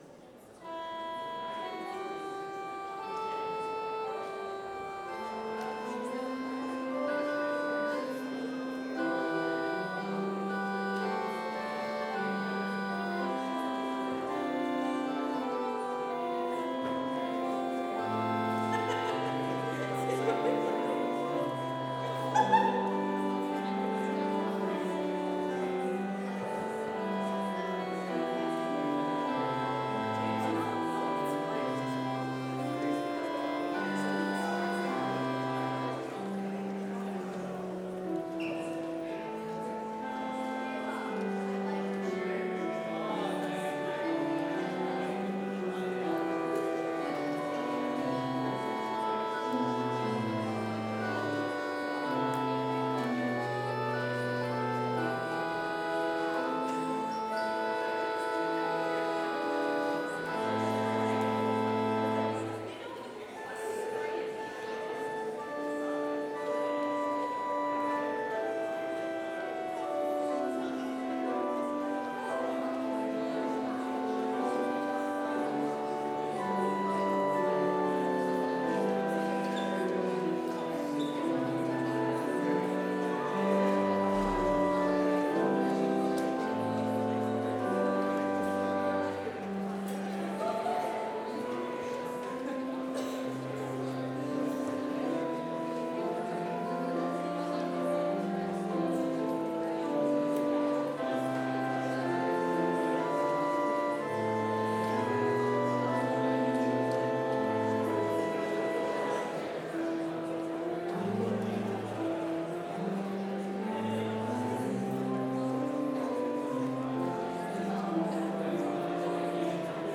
Complete service audio for Chapel - Tuesday, March 11, 2025